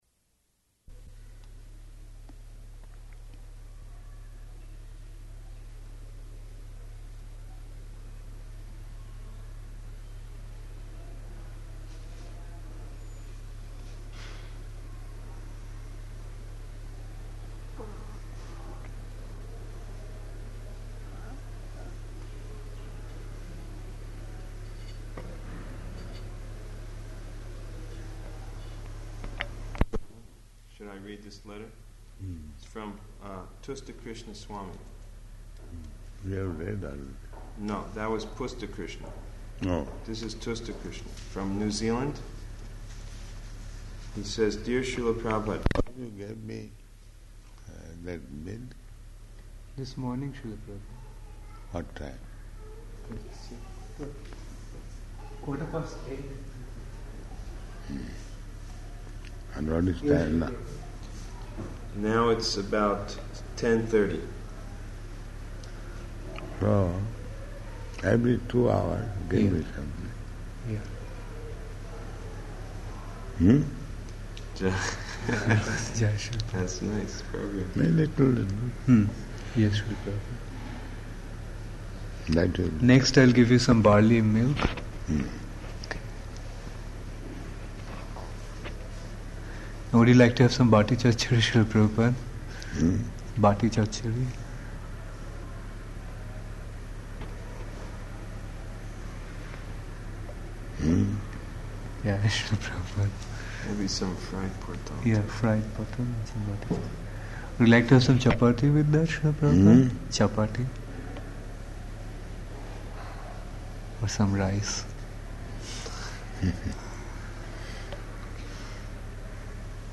Room Conversation
-- Type: Conversation Dated: November 3rd 1977 Location: Vṛndāvana Audio file